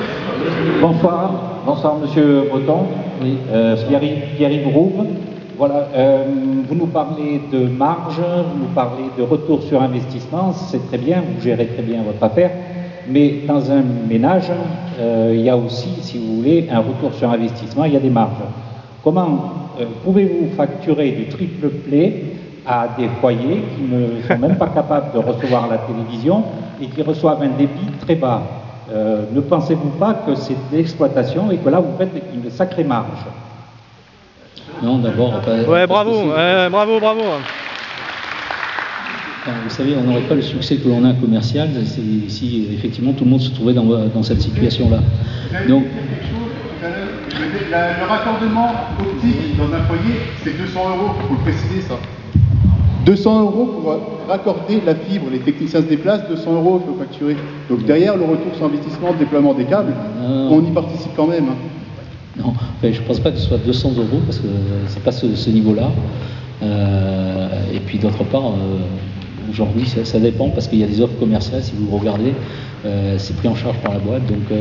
C.R. REUNION PUBLIQUE INTERNET HAUT DEBIT AU CRES : ORANGE demande aux créssois d'attendre des jours meilleurs .... des années plus exactement !